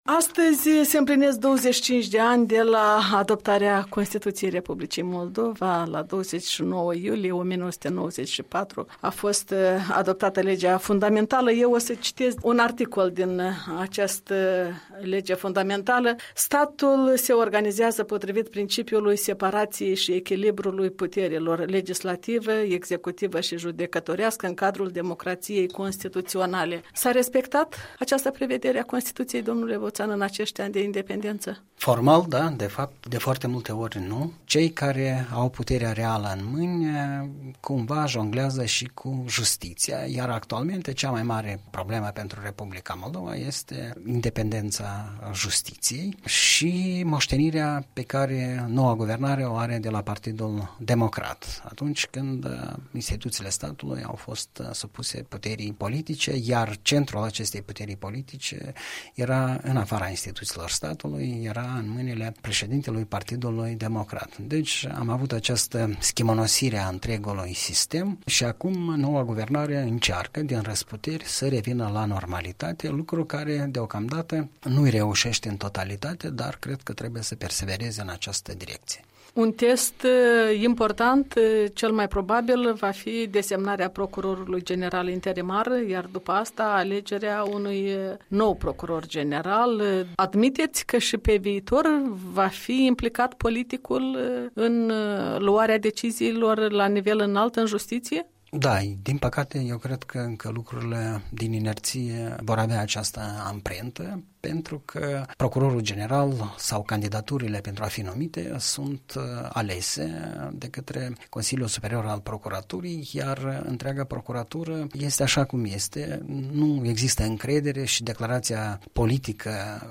Comentariu săptămânal, în dialog la Europa Liberă.